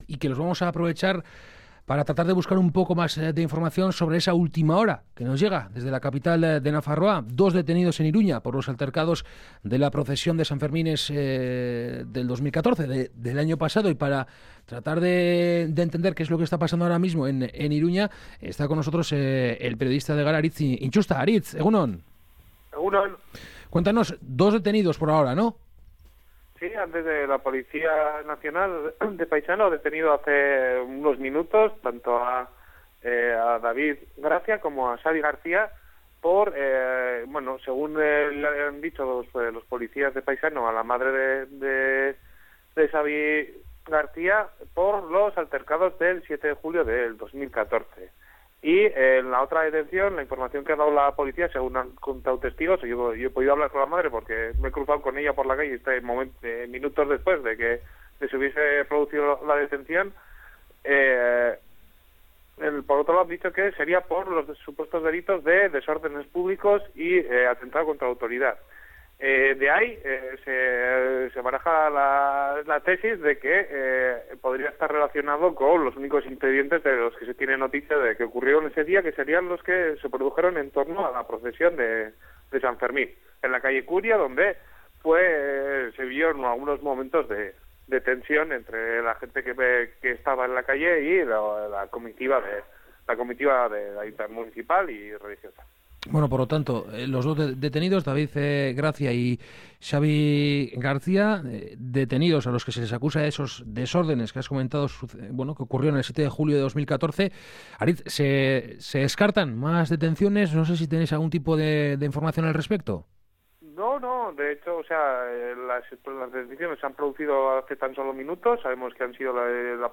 Kategoria: Elkarrizketak